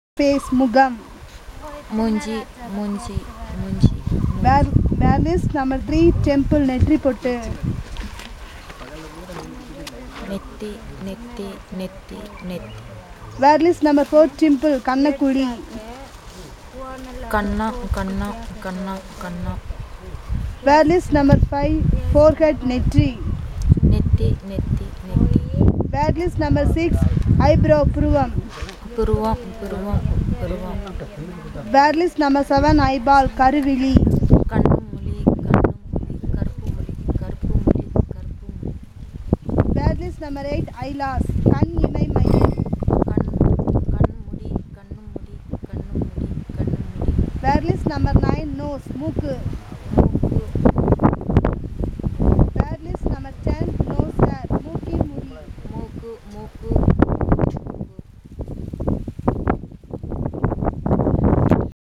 Elicitation of words about human body parts - Part 2